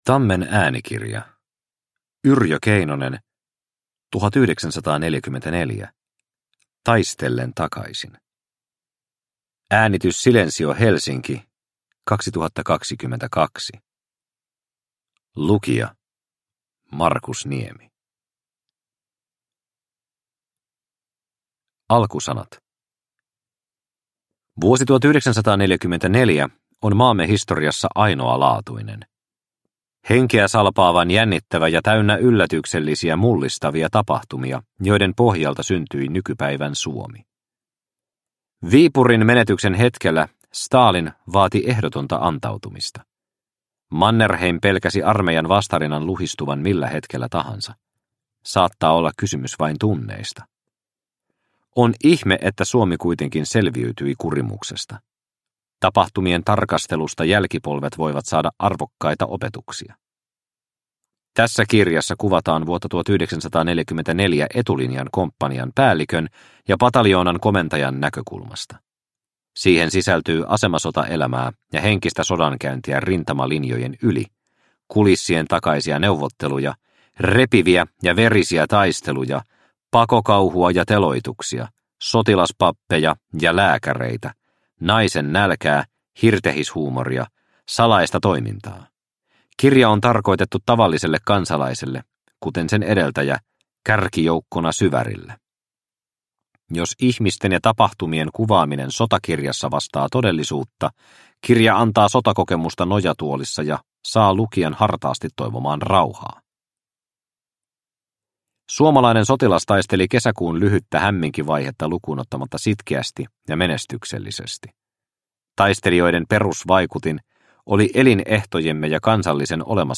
1944 Taistellen takaisin – Ljudbok – Laddas ner